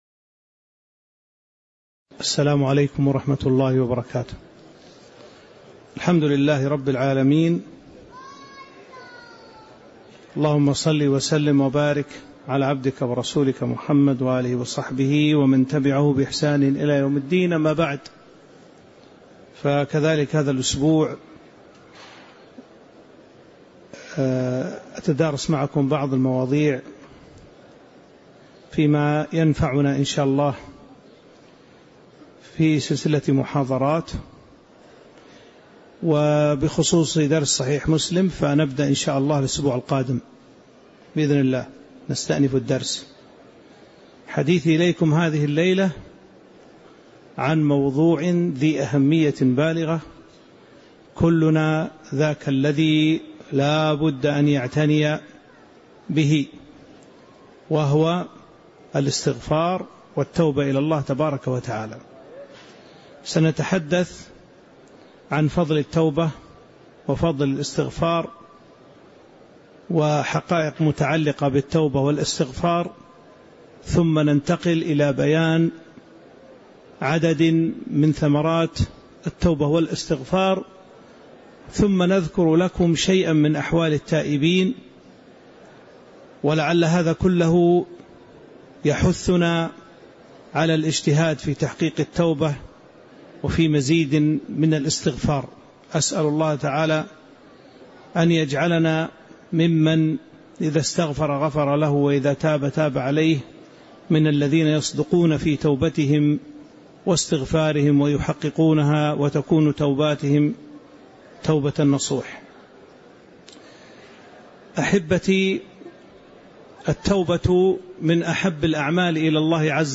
تاريخ النشر ٢٨ جمادى الآخرة ١٤٤٦ هـ المكان: المسجد النبوي الشيخ